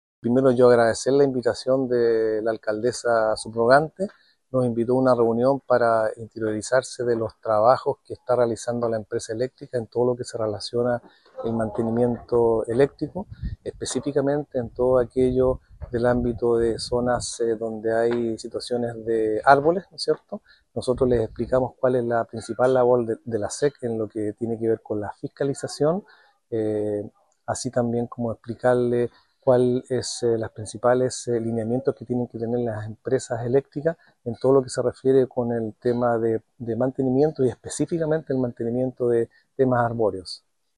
Mientras que el director regional de la SEC, Humberto Rovegno, comentó la labor fiscalizadora de la superintendencia a la empresa de generación eléctrica.